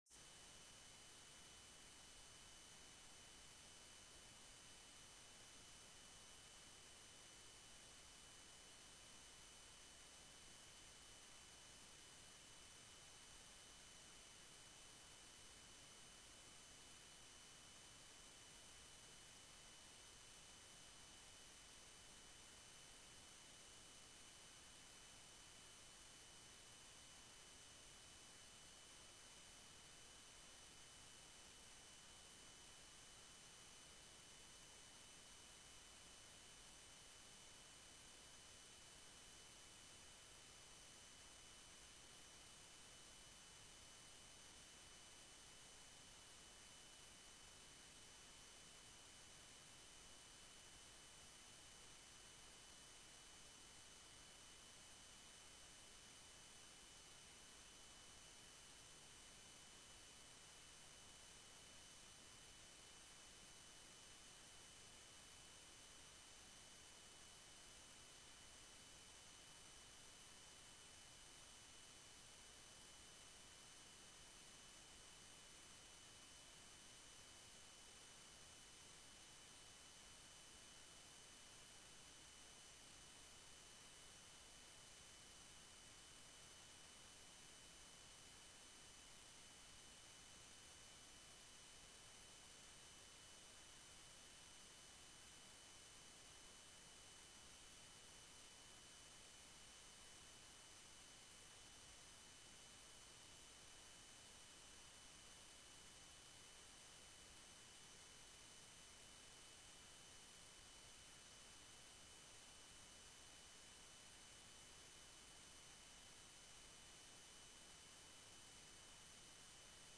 beeldvormende raad 15 mei 2014 19:30:00, Gemeente Doetinchem
Download de volledige audio van deze vergadering
Locatie: Raadzaal